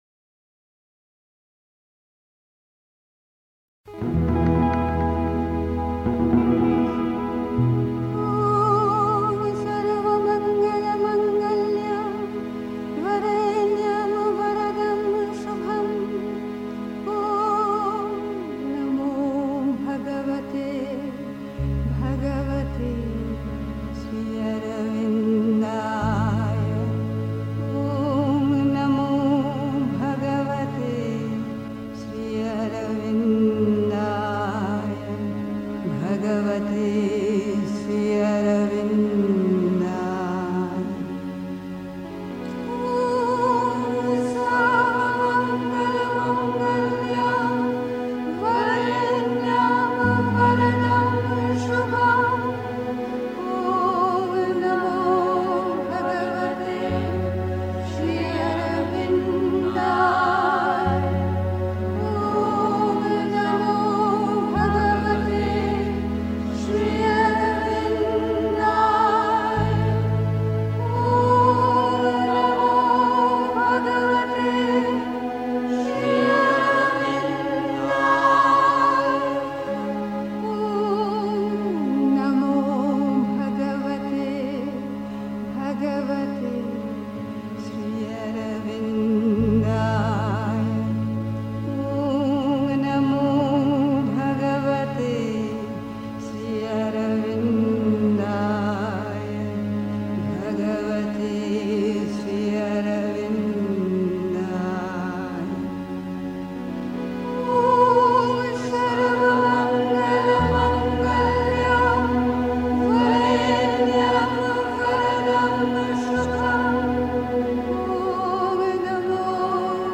1. Einstimmung mit Musik. 2. Dies wird von uns gefordert (Sri Aurobindo, CWSA, Vol. 23, pp. 110-11) 3. Zwölf Minuten Stille.